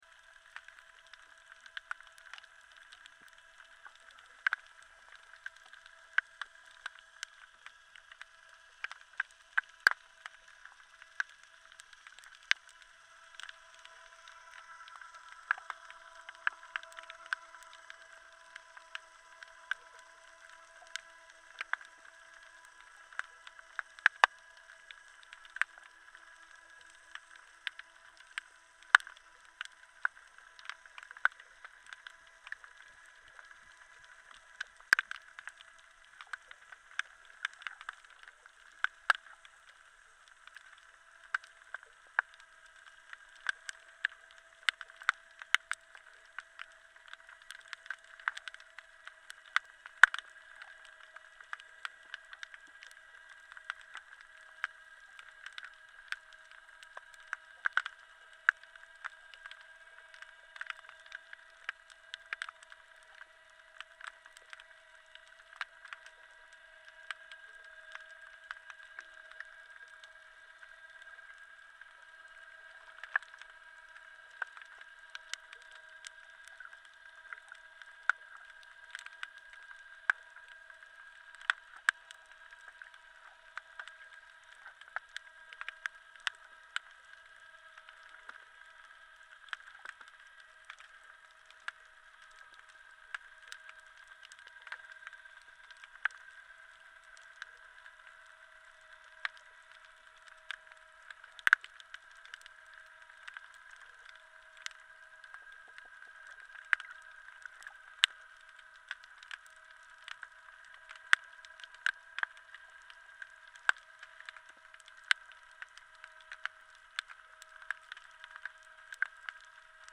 This is a hydrophone submerged in a pocket of Biscayne Bay off Museum Park near the MacArthur Causeway during rush hour. In it you hear ominous humming and revving coming from the Port of Miami, roughly 2km from the site.